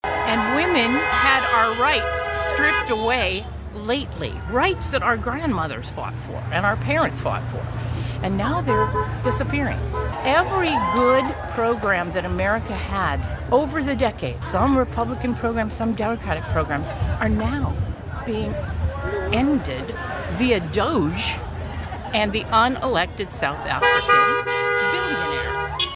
A large protest occurred at the Federal Courthouse in downtown Sioux Falls.